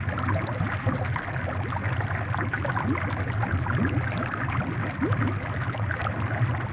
Bubbling_Water.ogg